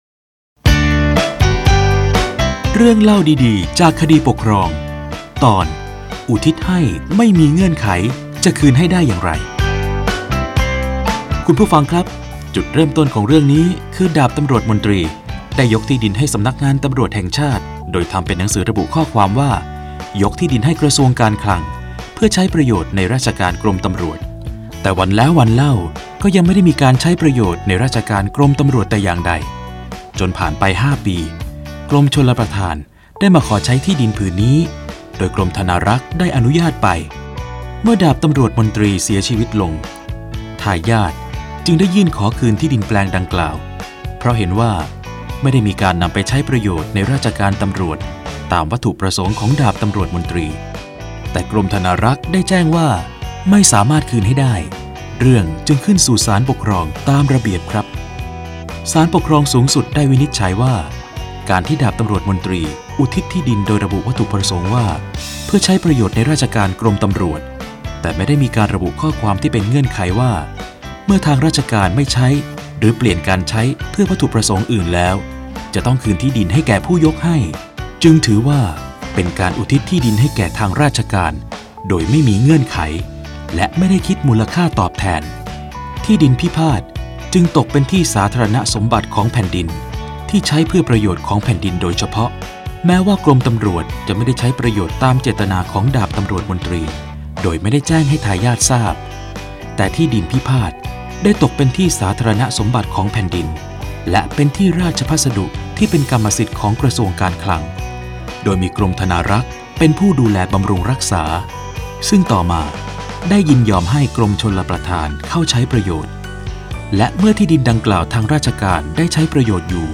คำสำคัญ : ตัวอย่างคดี, สารคดีวิทยุ, อุทิศให้ไม่มีเงื่อนไข...จะคืนให้ได้อย่างไร, คำพิพากษา, เรื่องเล่าดีดีจากคดีปกครอง, คดีปกครอง